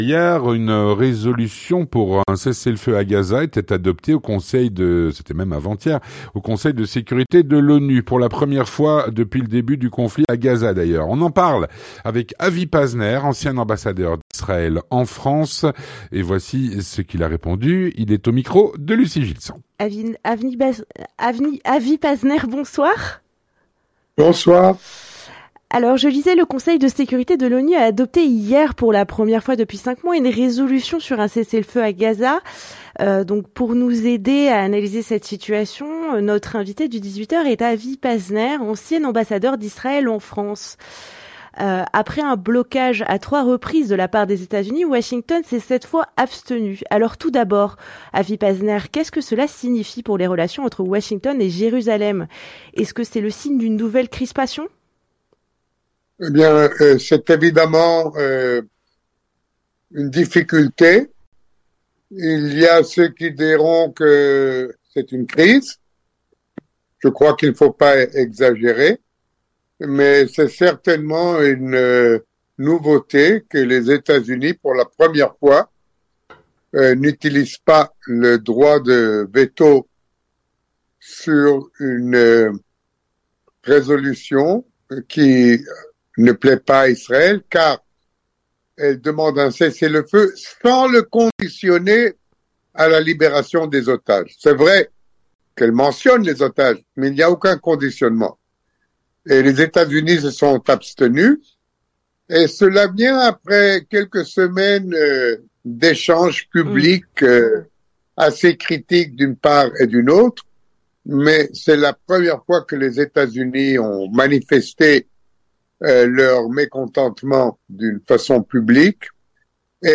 L'entretien du 18H - Une résolution pour un cessez-le-feu à Gaza a été adoptée au Conseil de sécurité de l’ONU, pour la première fois depuis le début du conflit . Avec Avi Pazner (27/03/2024)
Avec  Avi Pazner, Ancien ambassadeur d'Israël en France et en Italie